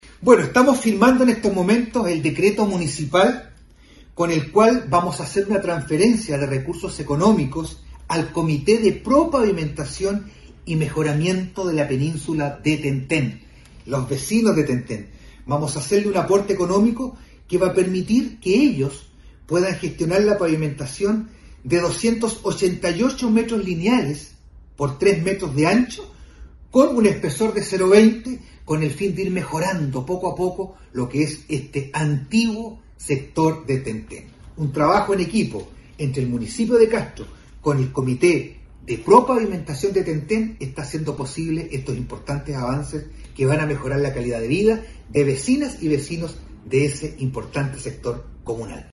ALCALDE-VERA-RECURSOS-PARA-TEN-TEN.mp3